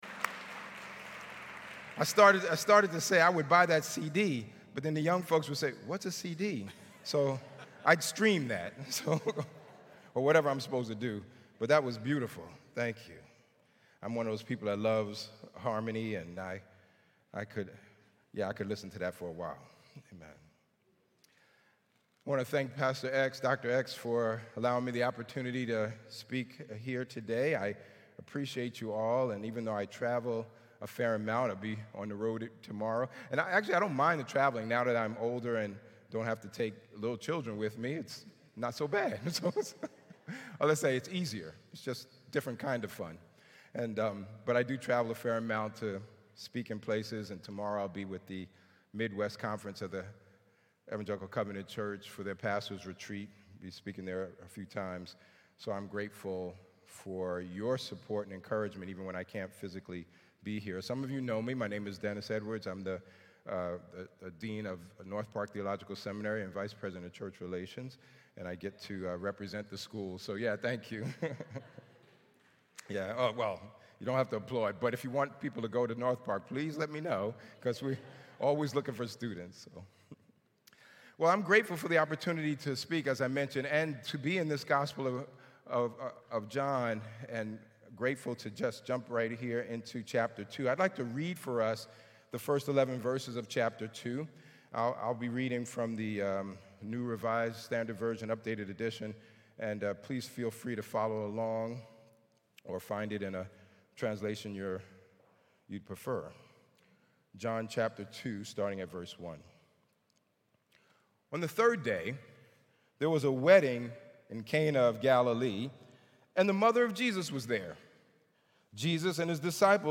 Sermon Who Do You Think You Are?